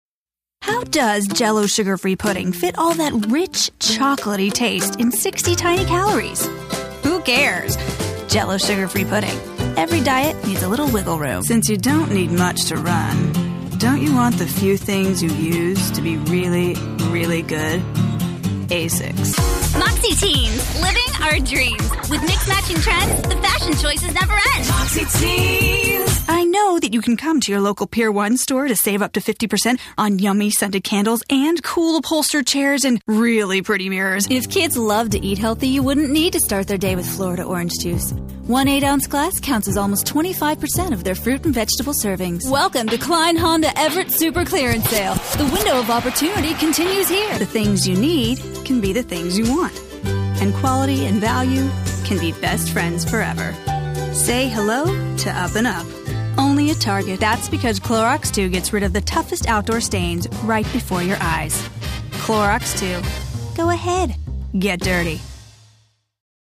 Fresh/hip, clear, bright, conversational ..and sassy/wry when it's called for.
englisch (us)
Sprechprobe: Werbung (Muttersprache):